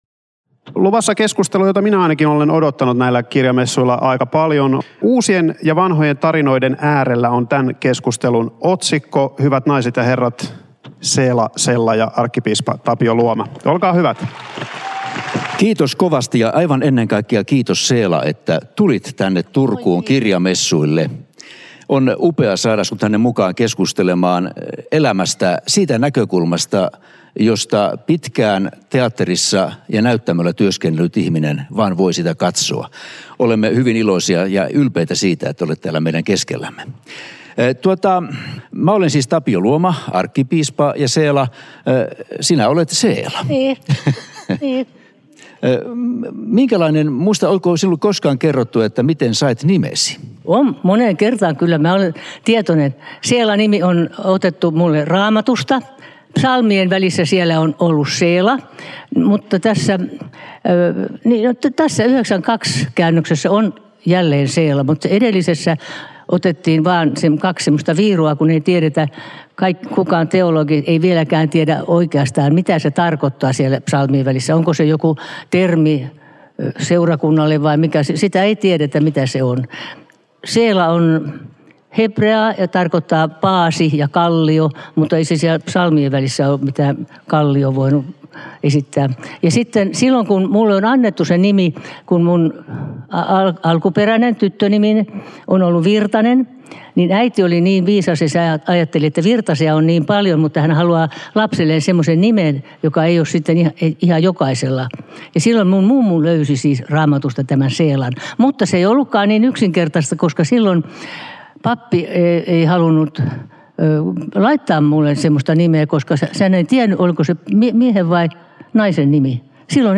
Mitä vuosikymmenet ovat opettaneet uusista aluista – ja kuinka puhaltaa tarinoihin uutta henkeä? Arkkipiispa Tapio Luoman vieraana rakastettu näyttelijä Seela Sella.